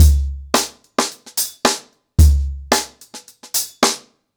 HarlemBrother-110BPM.17.wav